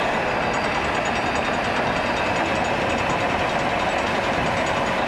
agricultural-tower-grappler-extend-loop.ogg